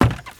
STEPS Wood, Creaky, Run 23.wav